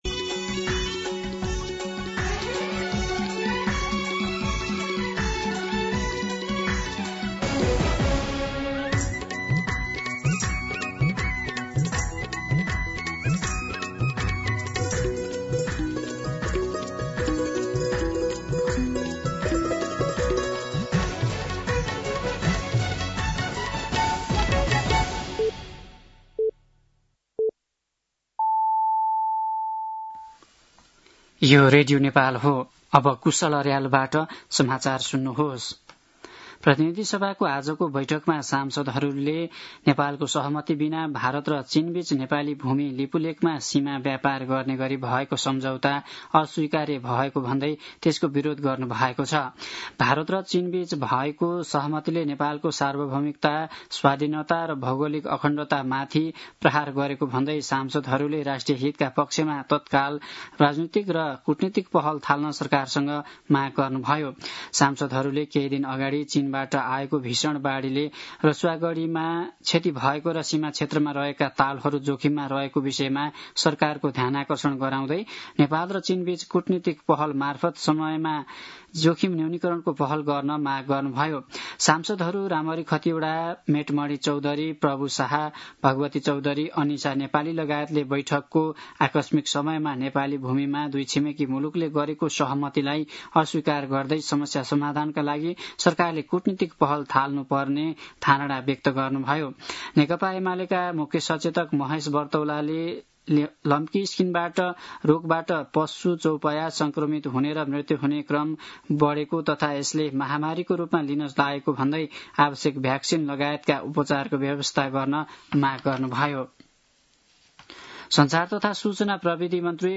दिउँसो ४ बजेको नेपाली समाचार : ६ भदौ , २०८२
4-pm-Nepali-News-1-2.mp3